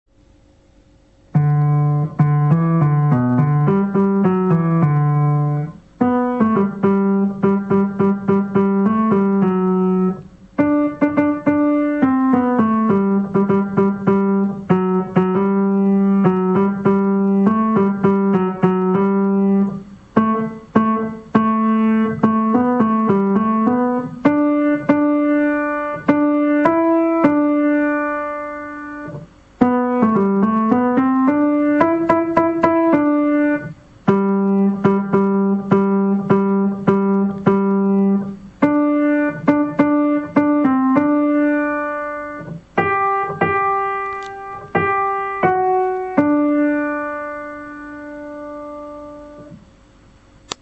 校歌の練習用音源
テノール パート (ダウンロードはここを右クリックして保存を実行)
SapporoNishiHighSchool_Piano_Tenor.mp3